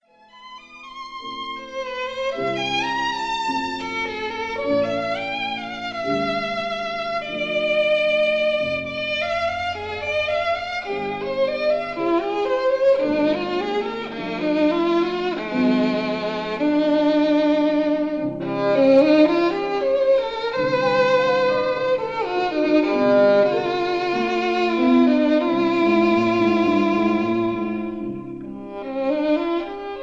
violin
piano